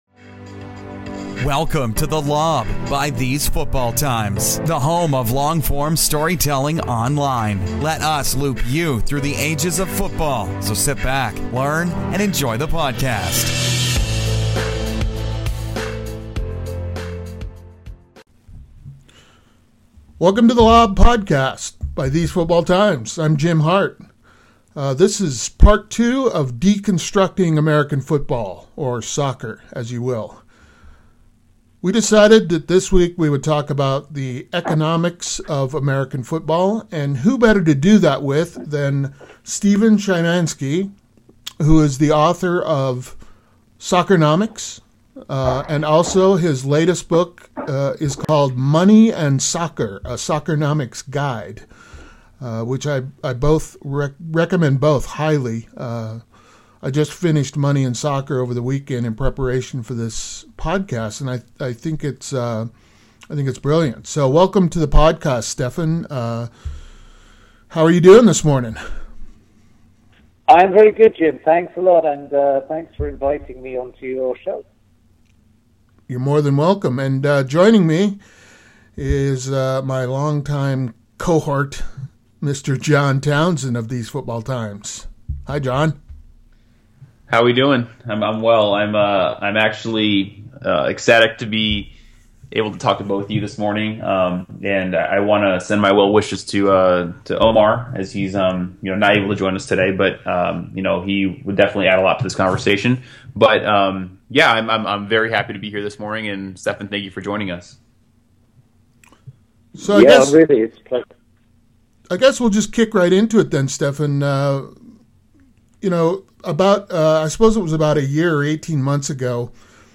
Deconstructing American Soccer (part II) is a discussion of the economics of the American version of the game. Joining us is award-winning economist from the University of Michigan and author of Soccernomics and Money and Soccer, Stefan Szymanski.